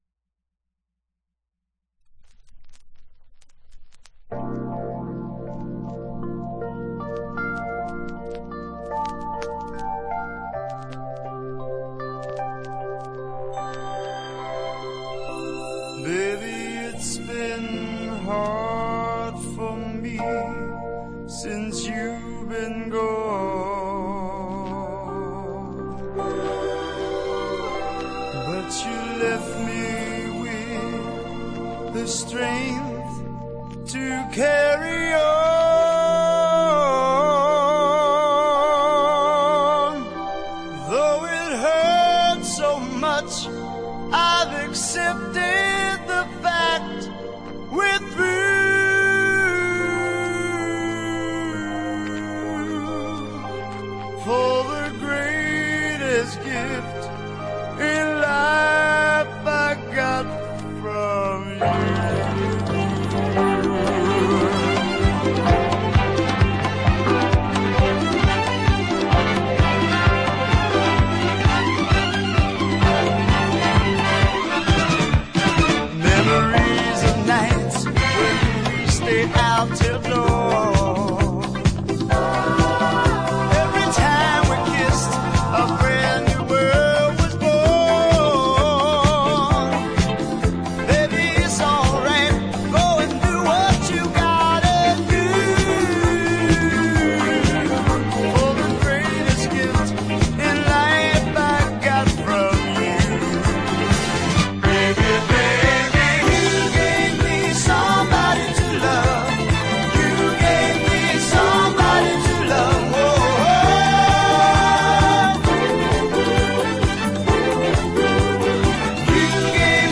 • 盤面 : EX+ (美品) キズやダメージが無く音質も良好